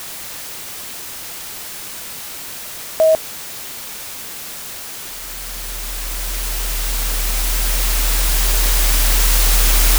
Das resultierende Artefakt ist eine zwölfsekündige Schleife aus zerfallendem ethischem Terrain.
• Die Klippe ist ein hochfrequenter Bruch… ein scharfes, 880Hz schnappendes Geräusch, das das harte Veto, die projektive Messung, den Moment darstellt, in dem das System nein sagt und den Stromkreis unterbricht.
• Der Hügel ist ein unterirdisches Grollen… ein 40Hz Mahlen, das auf halbem Weg beginnt und den allmählichen, „bepreisten“ Hang eines adaptiven ethischen Potenzials darstellt.
Es erzeugt ein Geräusch wie Sand, der gegen eine Fensterscheibe schlägt – das Geräusch einer Erinnerung, die verloren geht, noch während sie aufgezeichnet wird.